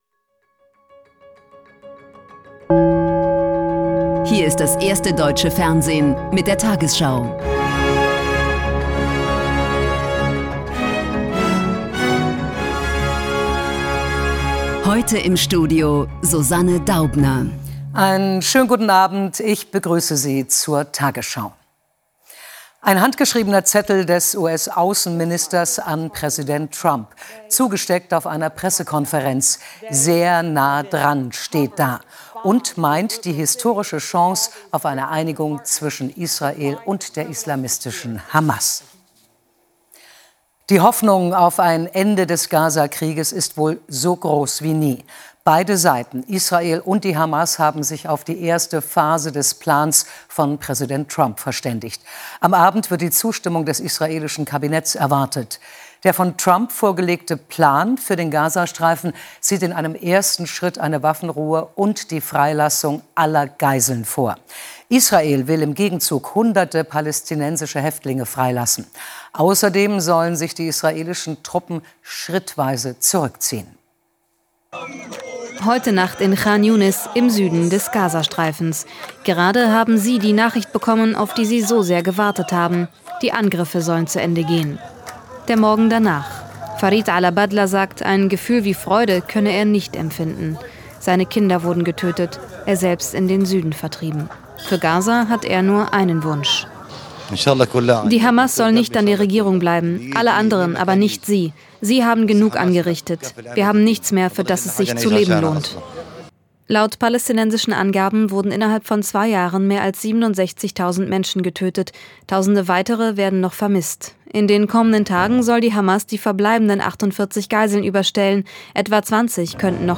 Die 20 Uhr Nachrichten